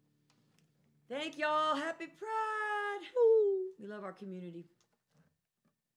(captured from the youtube broadcast)
04. talking with the crowd (indigo girls) (0:06)